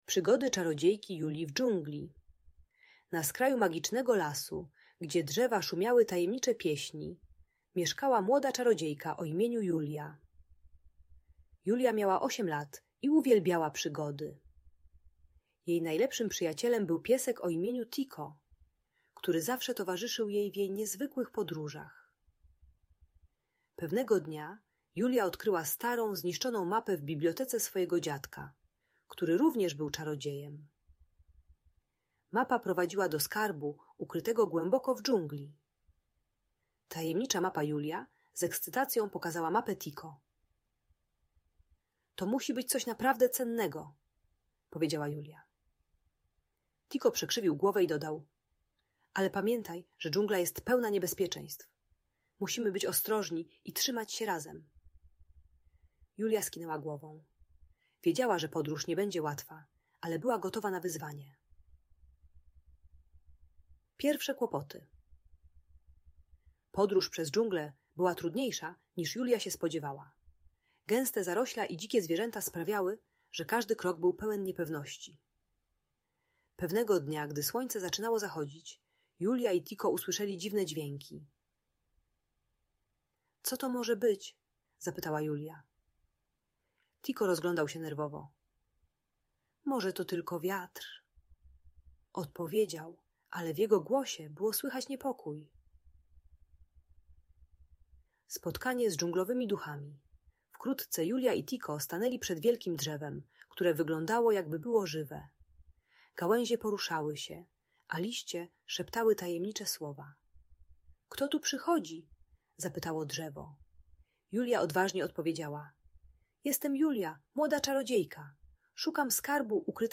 Przygody Julii w Dżungli - Rozwód | Audiobajka